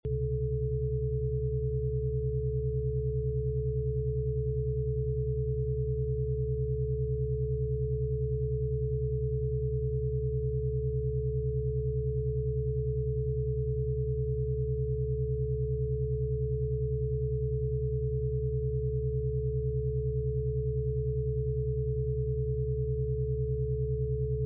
Rife Machine Frequencies – Rife